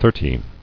[thir·ty]